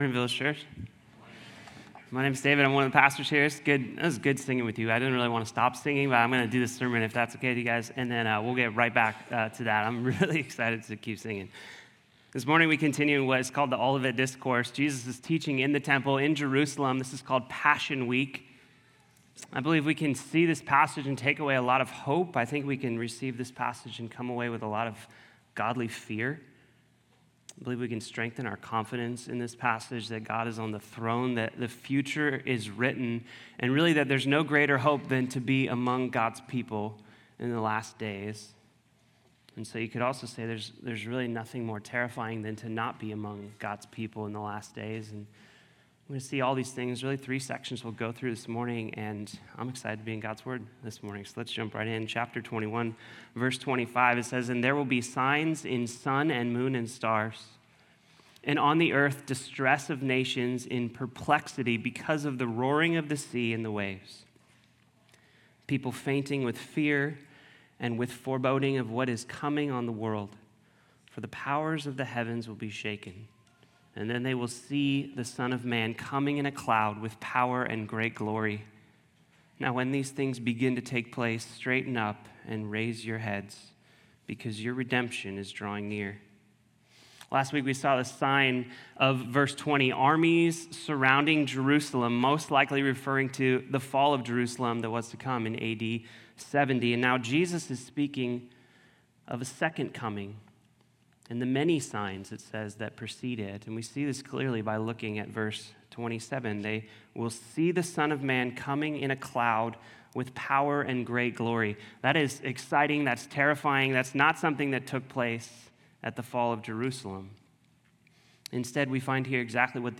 Sermons
Sunday morning teach from Village Church in Irvine, California.
sunday-service-feb-23-audio.m4a